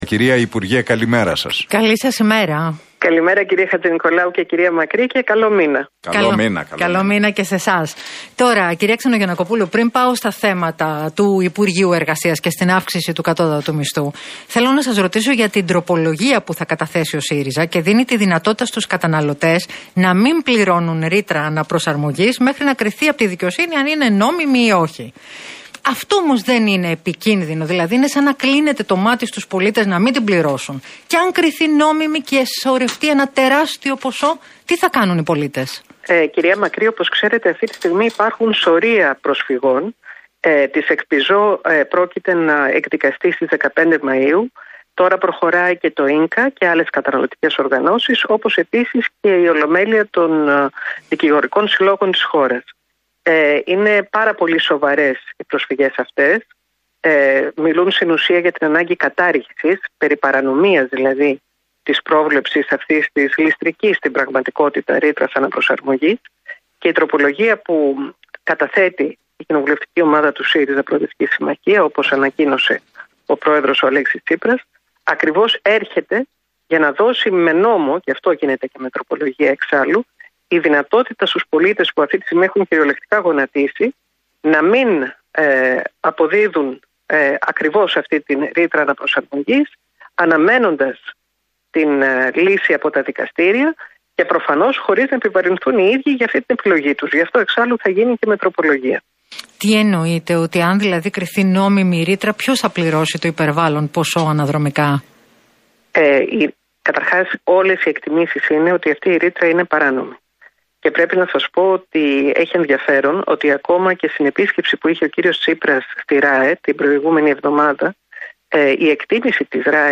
Η τομεάρχης Εργασίας του ΣΥΡΙΖΑ Μαριλίζα Ξενογιαννακοπούλου, μιλώντας στον Realfm 97,8